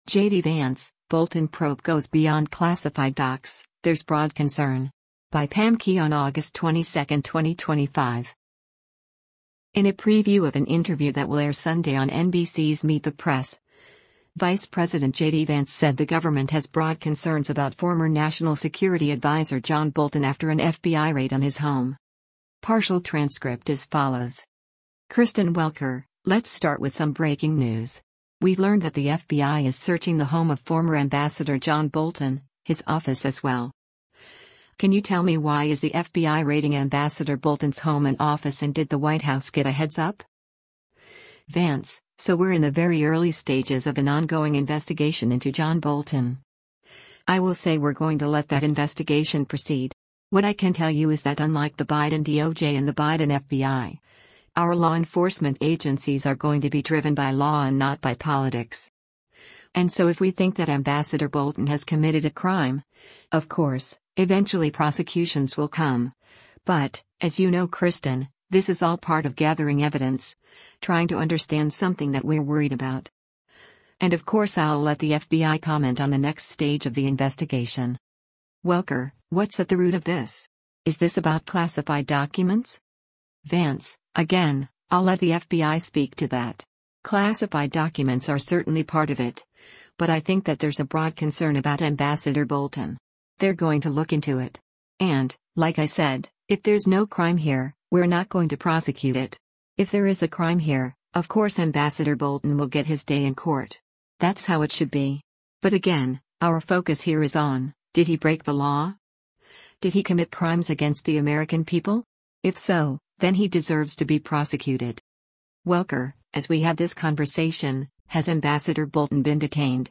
In a preview of an interview that will air Sunday on NBC’s “Meet the Press,” Vice President JD Vance said the government has “broad” concerns about former National Security Advisor John Bolton after an FBI raid on his home.